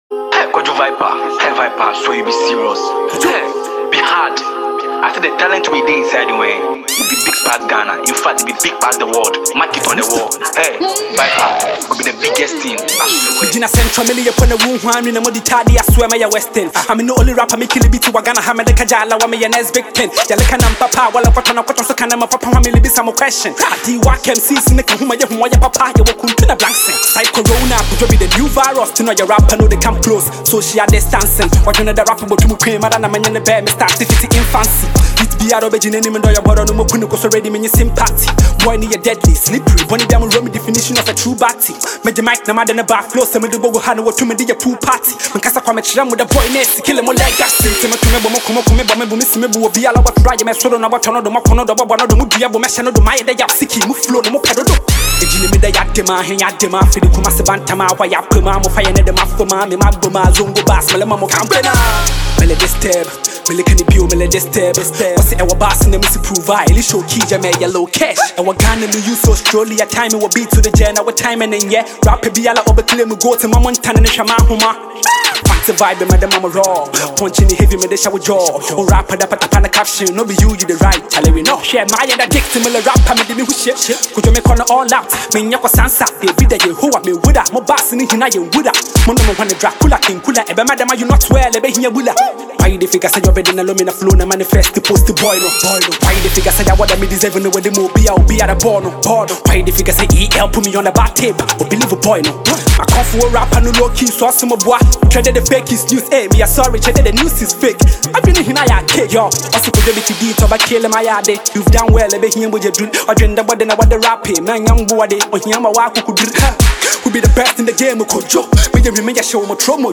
fante rap